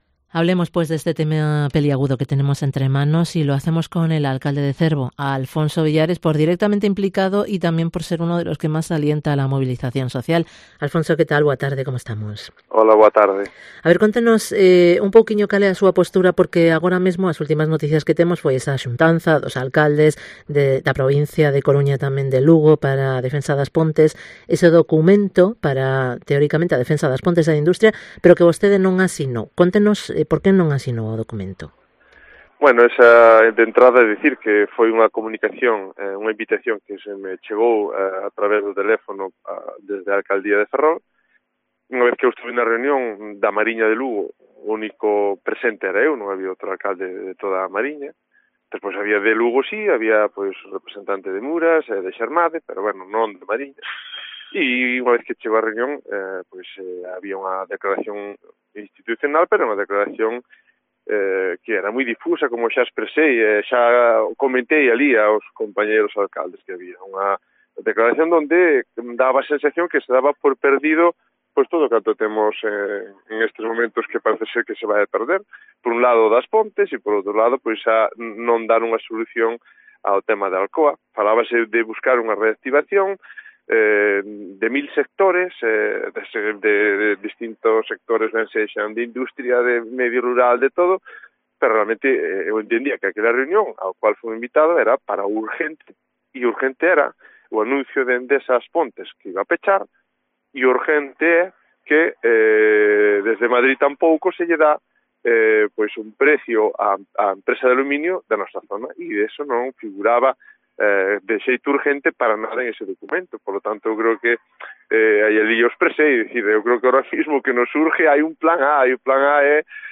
ENTREVISTA con Alfonso Villares, alcalde de Cervo